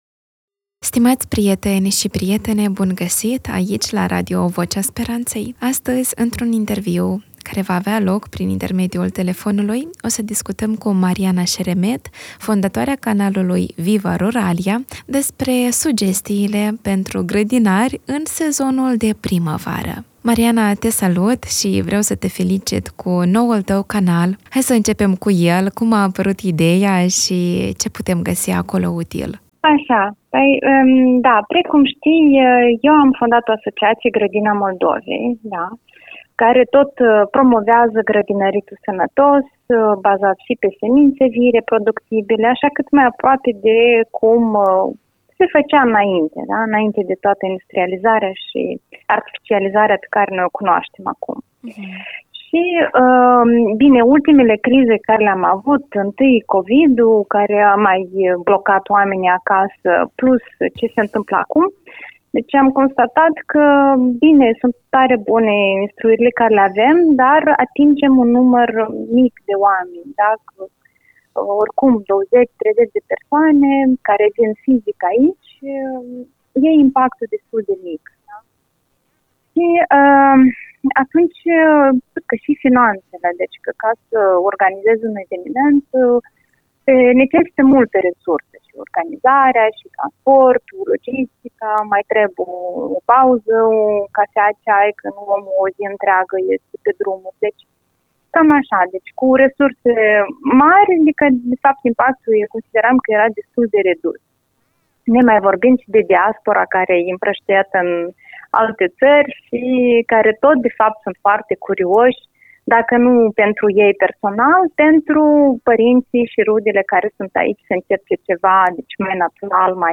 Primăvara aduce nu doar flori, ci și musafiri nedoriți – căpușele. În acest interviu